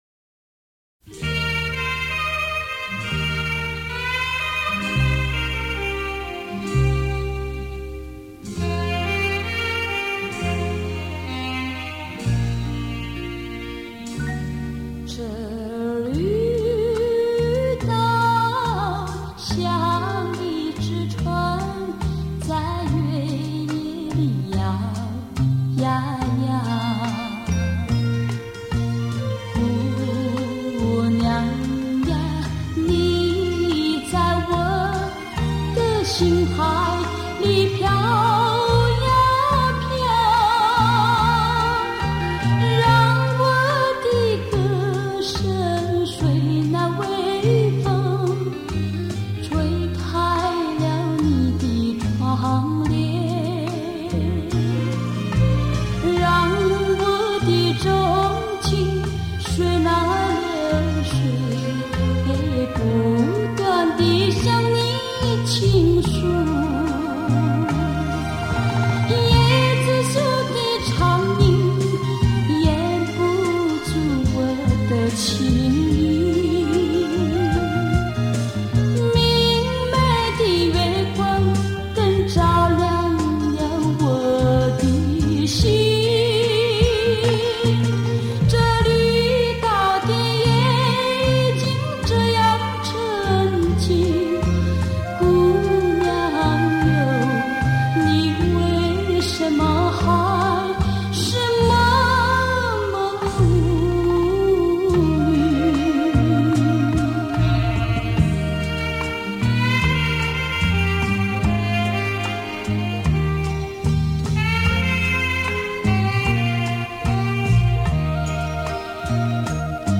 音色更接近模拟(Analogue)声效
强劲动态音效中横溢出细致韵味